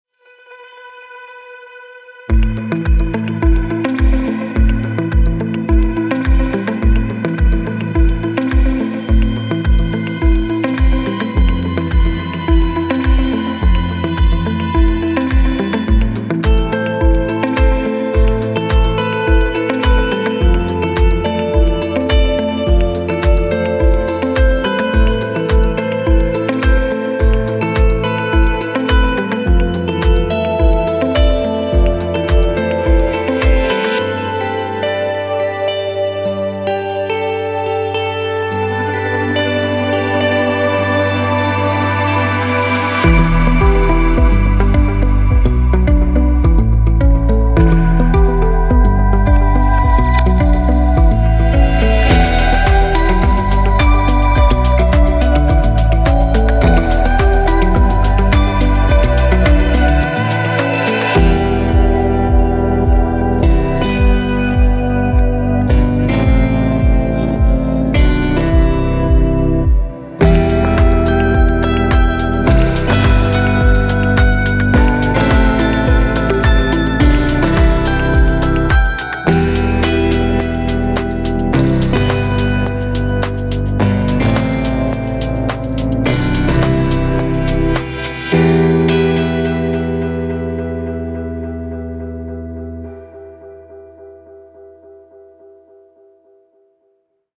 A bundle of atmospheric electric guitars
The twangy, atmospheric tension
The mellow warmth and emotive glow
moody, reverberant tension
mellow, intimate warmth